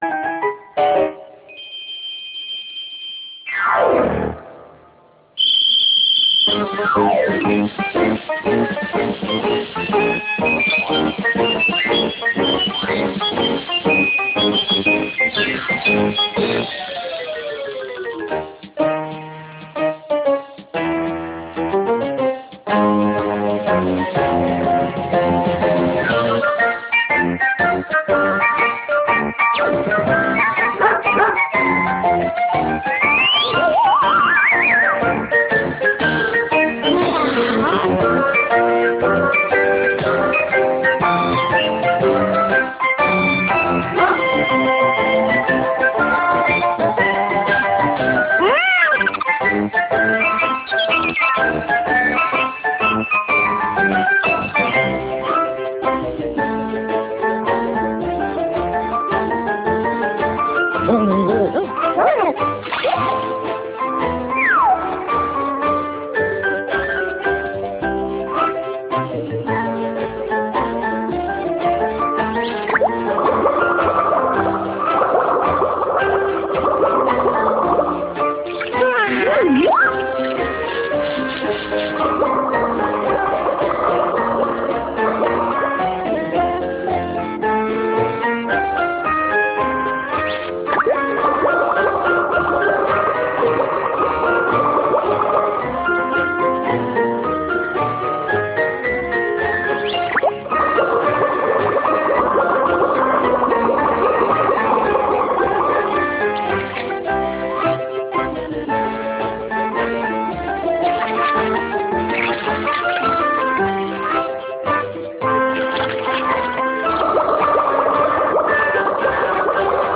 An animated series for young children, featuring a lordly dog and the cat and mouse servants who must prepare his daily meal. As lunch must be ready at twelve sharp, the cat and the mouse often have to improvise. After all, catching a trout, milking a goat or picking plums are no easy tasks.Please note: This series is without narrative but has a few short title-links which need to be dubbed.